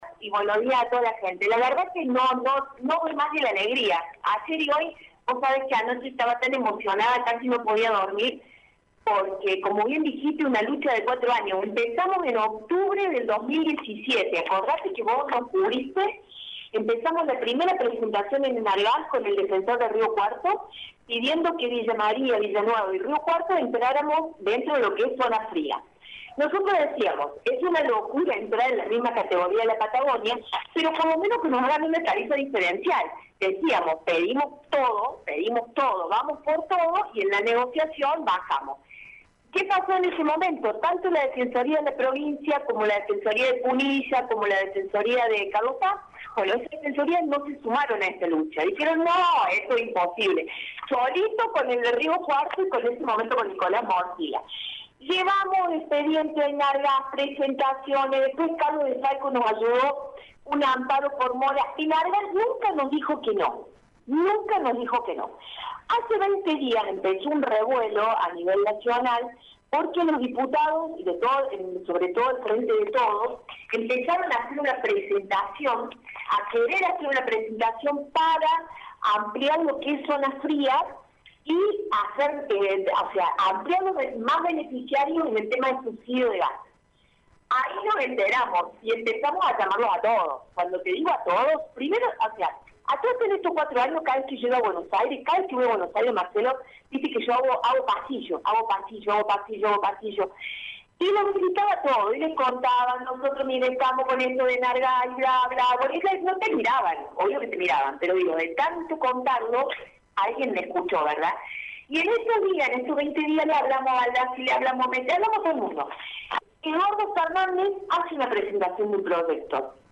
La propia Alicia Peresutti habló con Radio Show y explicó por qué está Villa María y Villa Nueva más cerca de ser declarados Zona Fría y que beneficios traería en materia de tarifa.